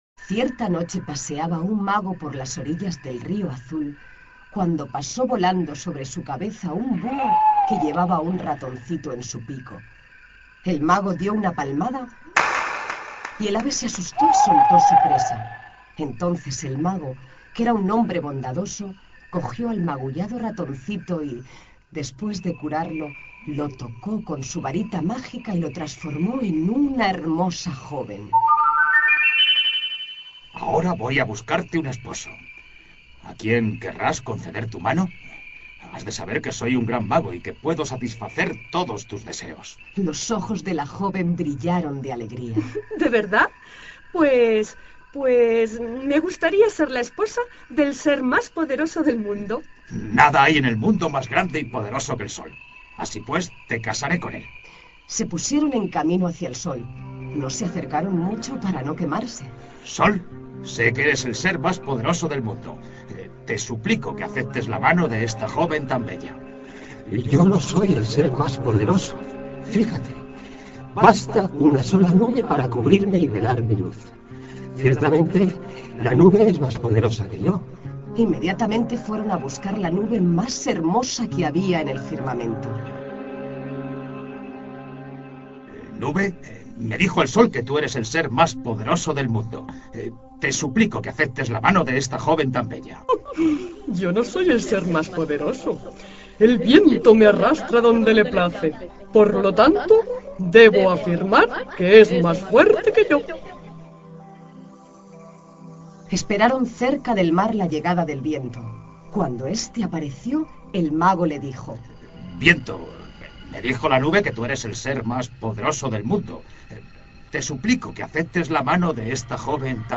palmada
cuento
distorsión
Sonidos: Cuentos infantiles